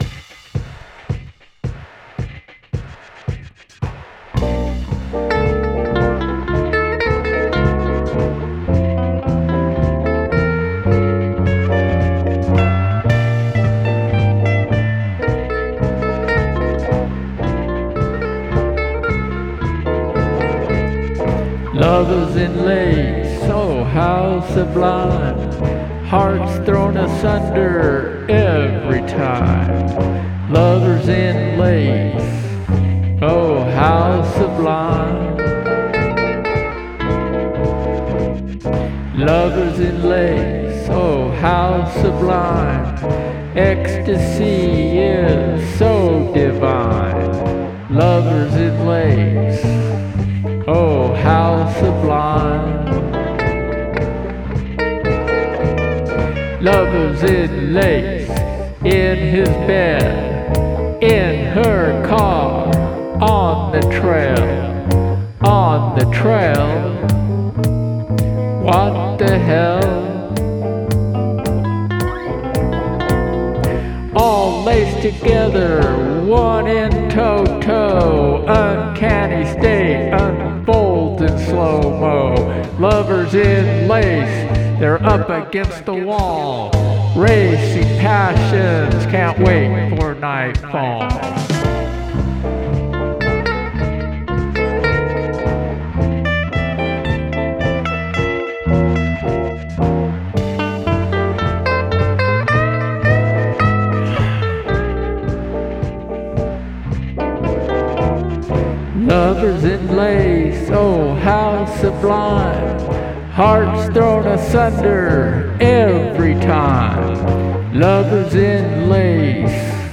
Guitars, Bass, and Percussion
Imagine yourself in the low light of a smokey bar gazing into your lover’s eyes with Lovers in Lace playing in the background.